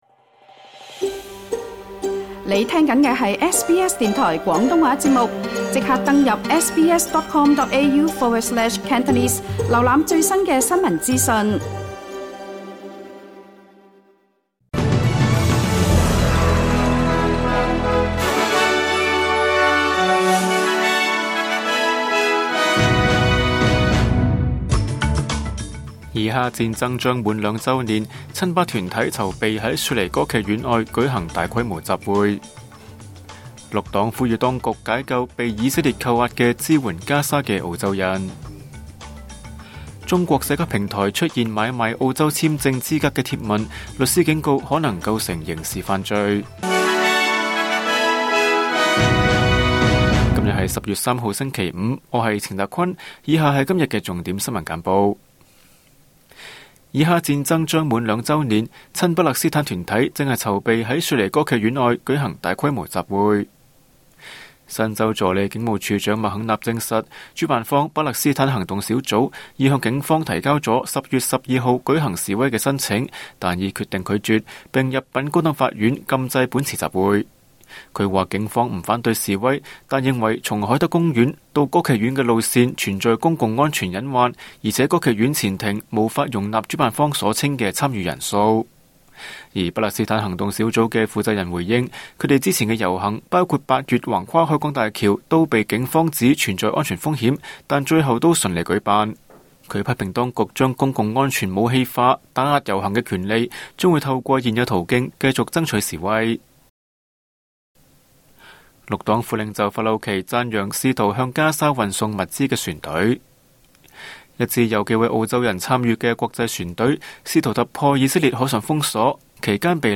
SBS晚間新聞（2025年10月3日）